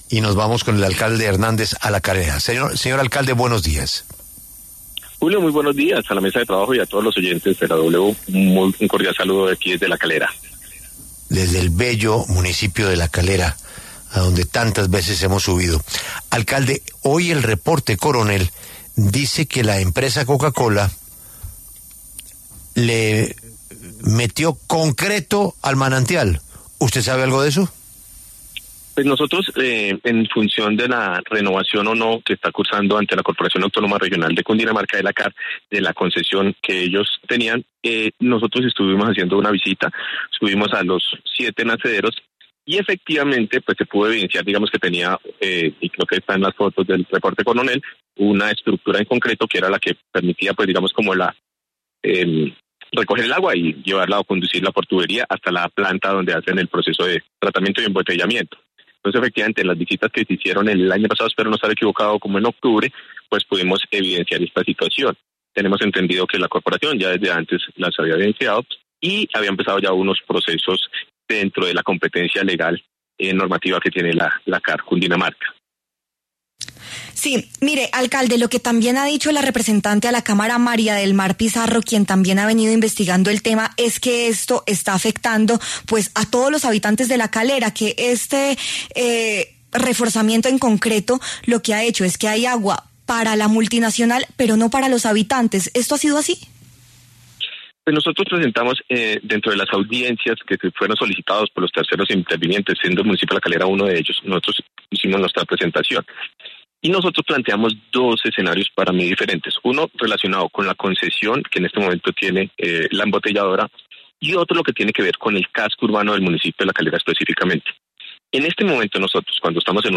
El alcalde de La Calera, Juan Carlos Hernández, respondió en La W a la denuncia de que la multinacional Coca-Cola habría cubierto los manantiales con concreto.